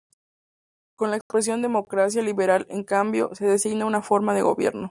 Read more Adj Noun Frequency C2 Hyphenated as li‧be‧ral Pronounced as (IPA) /libeˈɾal/ Etymology From Latin līberālis (“befitting a freeman”), from līber (“free”).